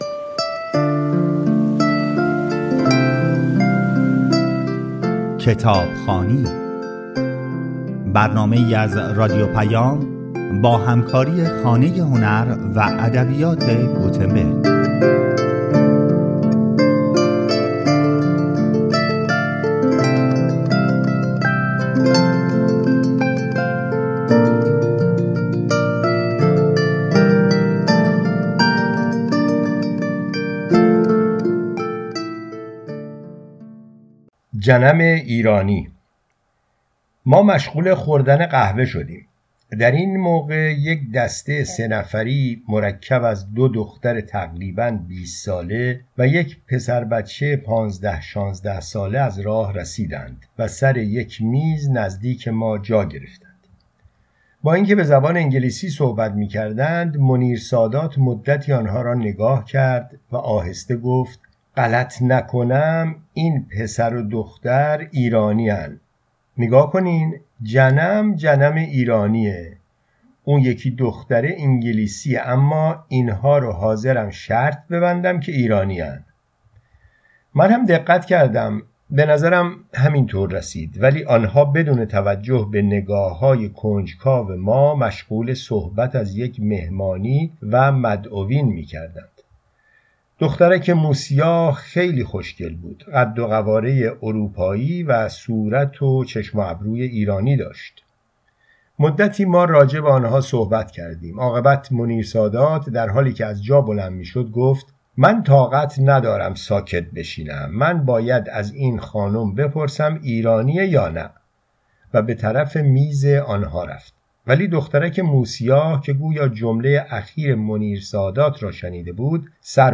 در برنامه کتابخوانی